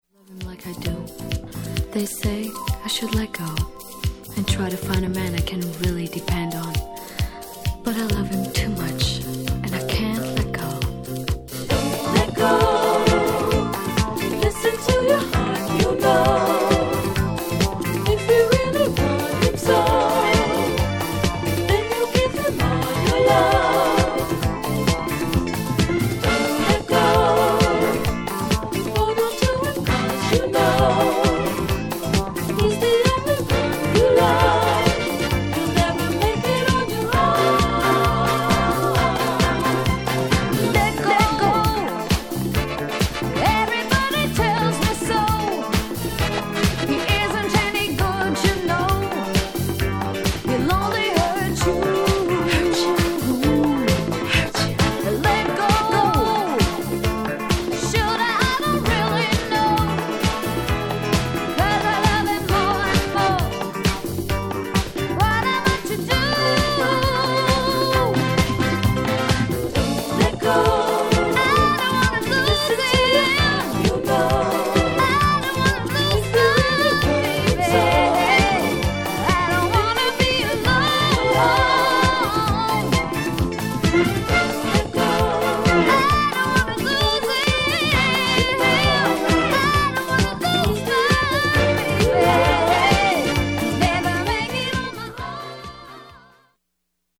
but the light and melodious 1979 version is also popular!